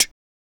Mouth Interface (1).wav